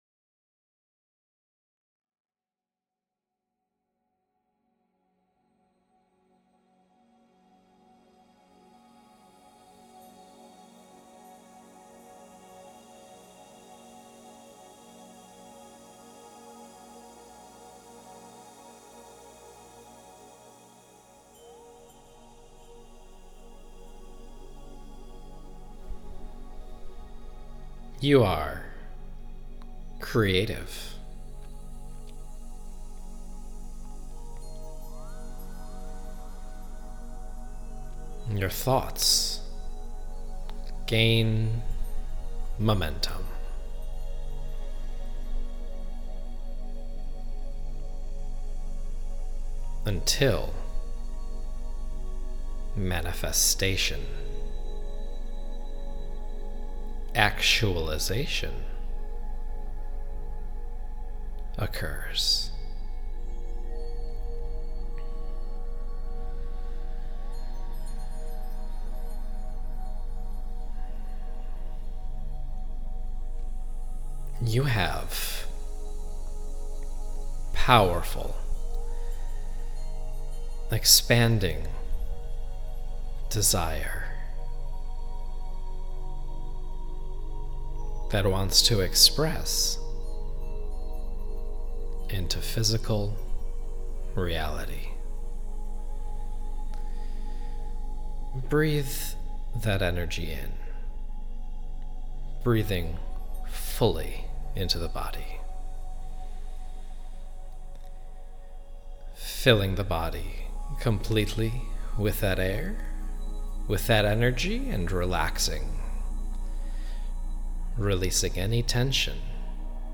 Inspired+Manifestation+Meditation.m4a